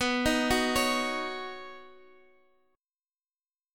Badd9 Chord (page 2)
Listen to Badd9 strummed